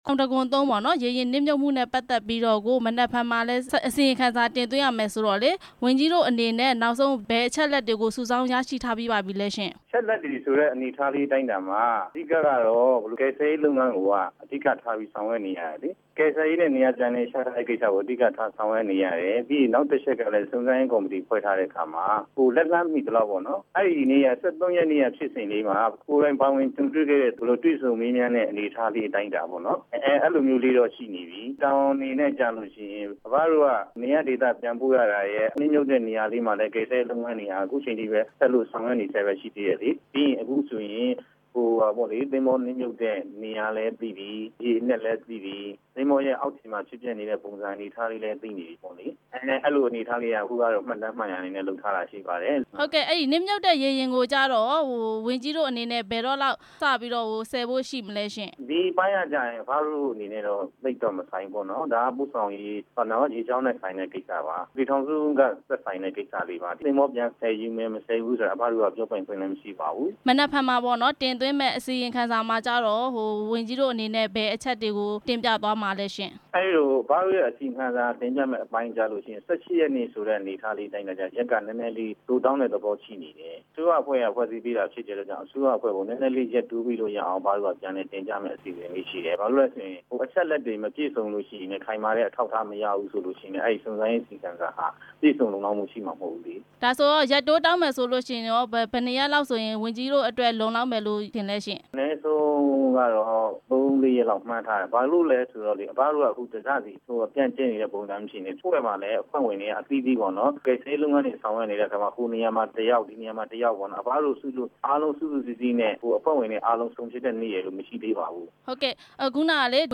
ရခိုင်ရေယာဉ်နစ်မြုပ်မှု အကြောင်းမေးမြန်းချက်